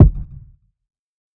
TC2 Kicks19.wav